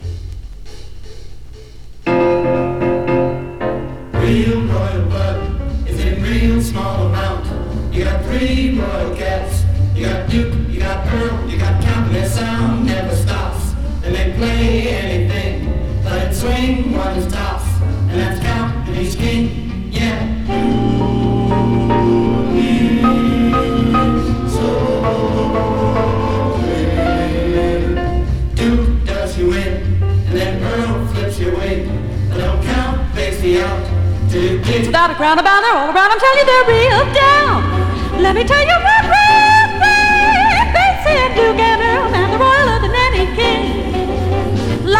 ヒップに、クールに、絶妙なヴォーカルアンサンブル、軽やかに小粋にビッグ・バンド・スウィング・ジャズが楽しい良盤。